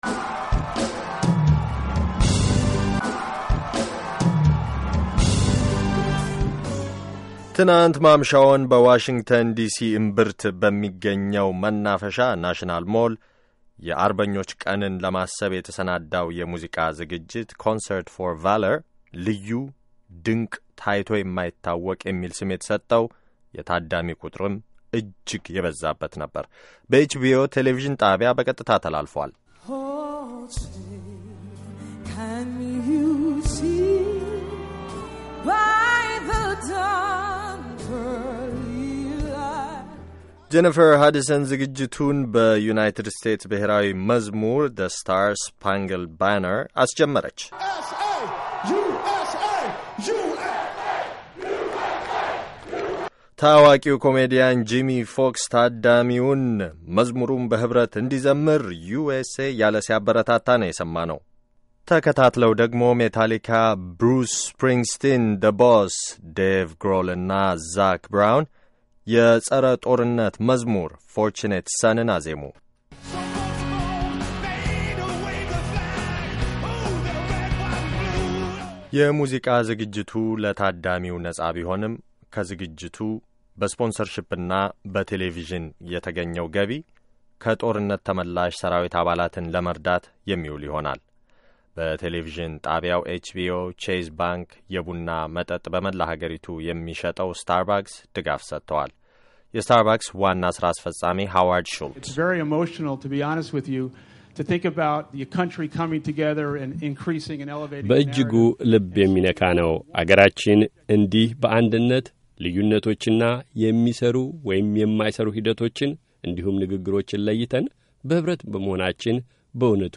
የመጀመሪያው ዓለም ጦርነት ያበቃበት 100ኛ ዓመት ከዚህ በዓል ጋር ኩታ በመግጠሙ፤ ፈንጠዝያውና የማስታዎሻ ስነስርዓቱን በእጅጉ አድምቆታል። ትናንት ማምሻውን ታዋቂ አሜሪካዊያን ሙዚቀኞች በዋሽንግተን ዲሲ ታላቅ የሙዚቃ ዝግጅት አቅርበዋል።